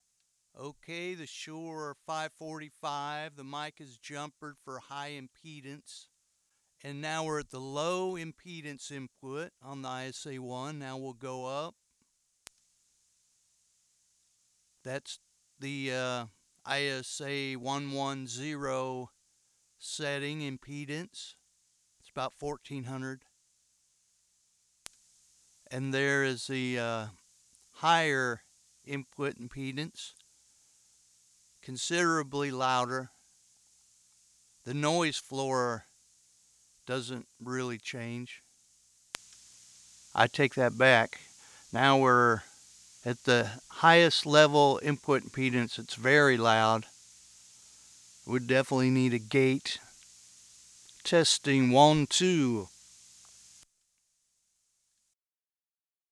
Heres a mp3 spoken word, 545 set to HighZ setting on mic. Using a ISA One I went through the 600, 1400, 2400, 6800 ISA Z range. (The hsssing is a bit of a mystery as it doesnt seem to do that in Low Z.?)